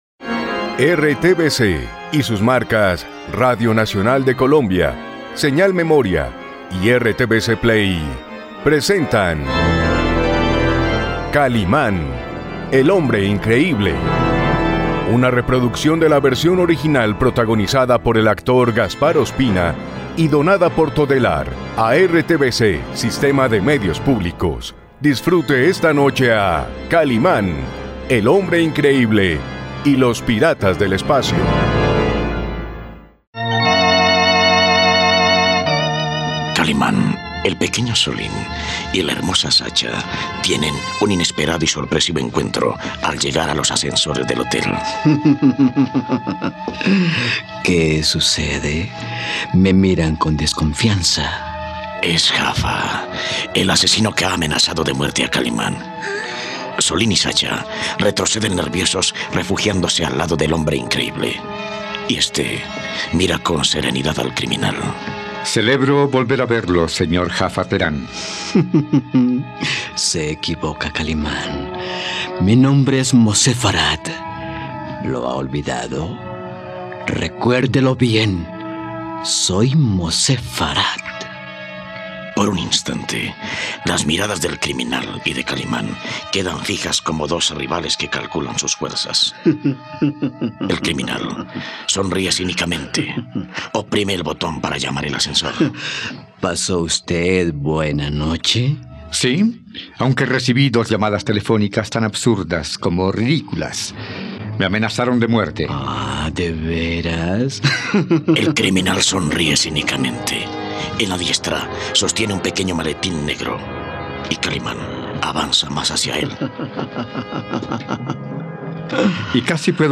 No te pierdas la radionovela de Kalimán y los piratas del espacio aquí, en RTVCPlay.